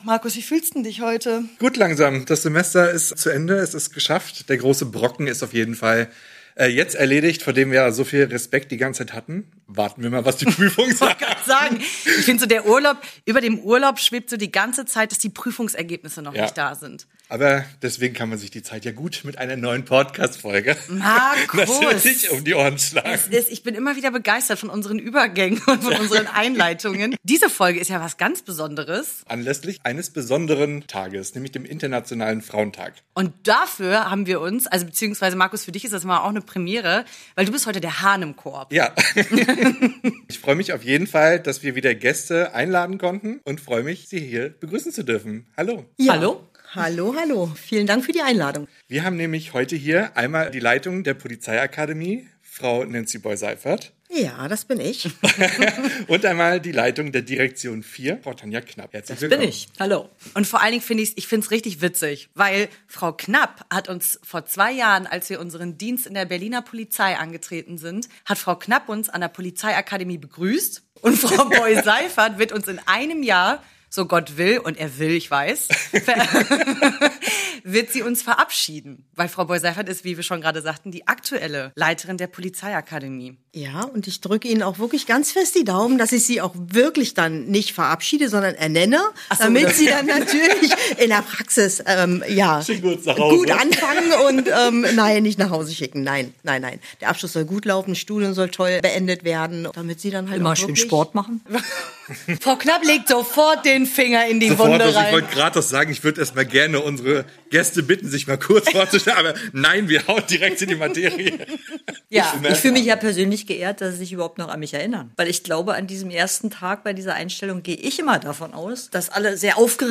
Wir haben zwei Frauen zu Gast, die einen unterschiedlicheren Weg gar nicht hätten haben können. Trotzdem sind beide jetzt da, wo sie nicht mehr wegzudenken sind: In hochrangigen Führungspositionen.